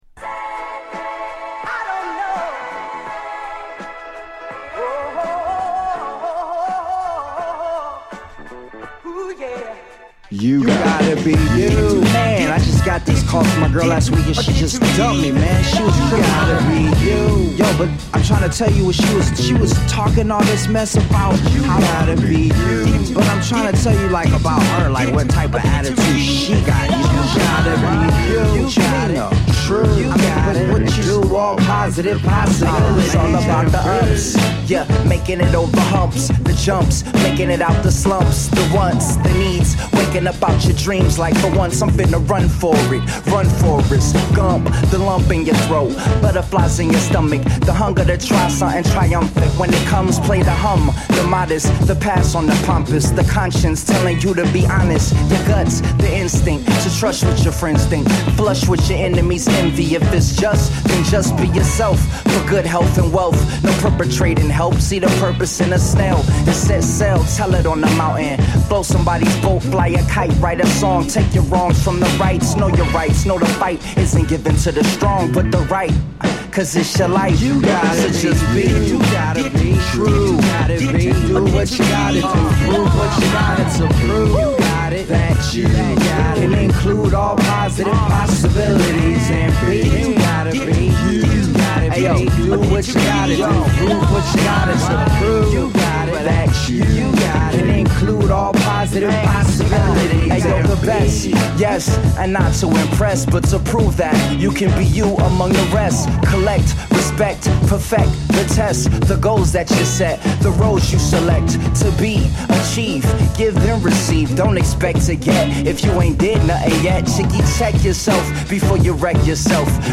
ハートフルなソウル・サンプルにラグドなドラムをハメたA1